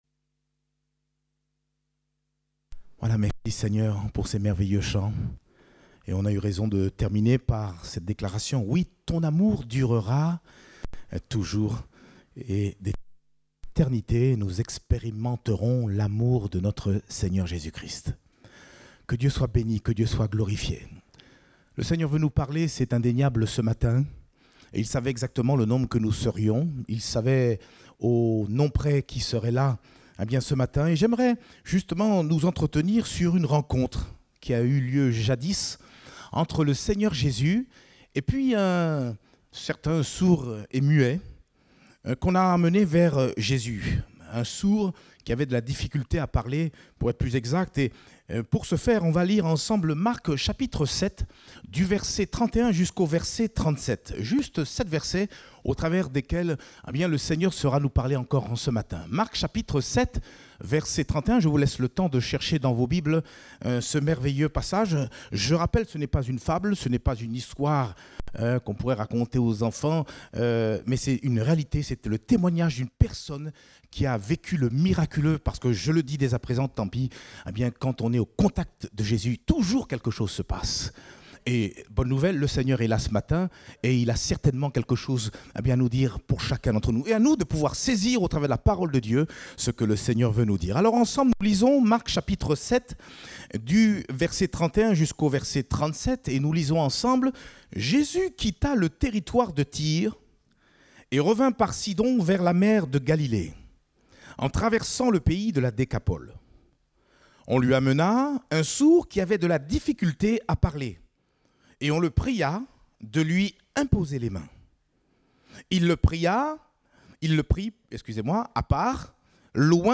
Date : 11 avril 2021 (Culte Dominical)